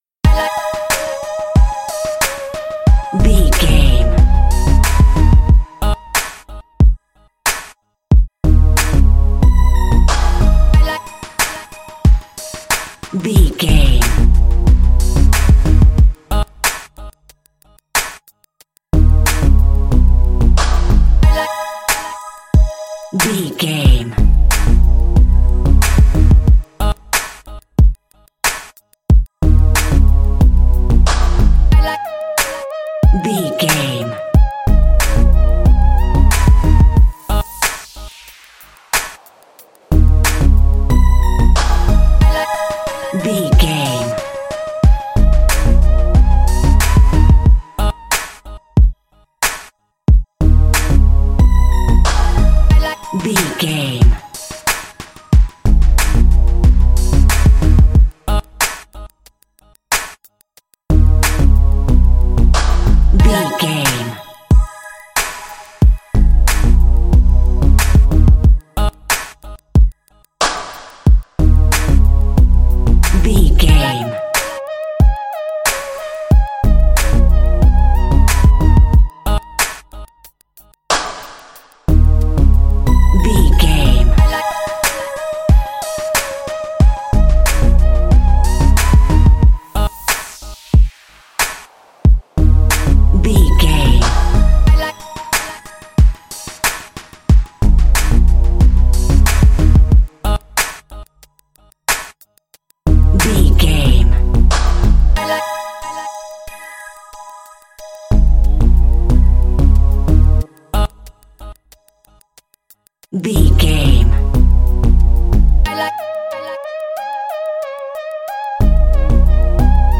Aeolian/Minor
G#
drums
electric piano
90s